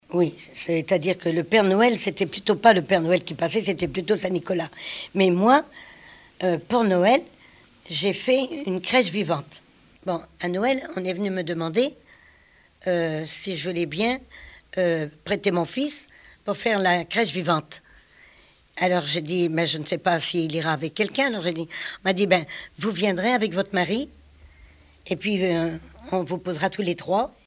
Témoignages liés aux rituels du calendrier
Catégorie Témoignage